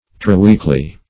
Triweekly \Tri"week`ly\, a. [Pref. tri- + weekly.]